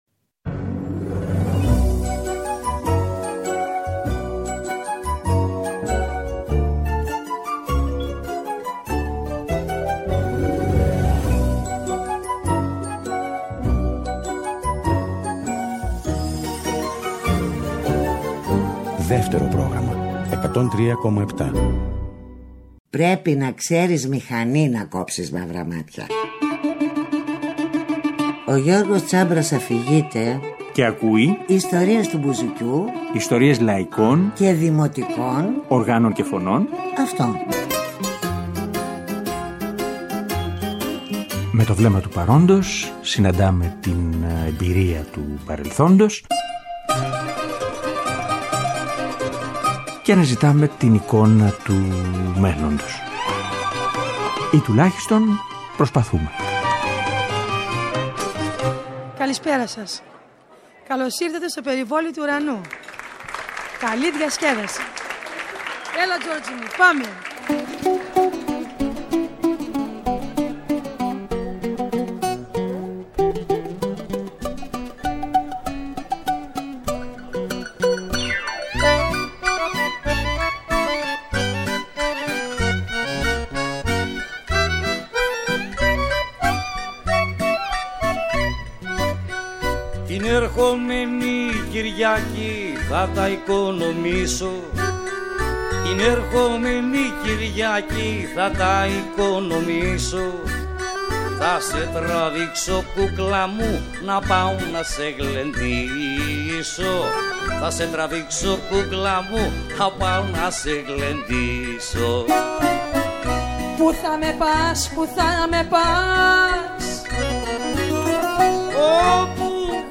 Φέτος «κλήρωσε» ένα ρεμπέτικο, ένα λαϊκό ταξίδι μέσα από τα χρόνια. Είπαμε να περιπλανηθούμε σε ιστορικά αλλά και σε νεώτερα στέκια του λαϊκού τραγουδιού. Ακούγοντας ζωντανές ηχογραφήσεις με ιστορικά αλλά και σύγχρονα πρόσωπα.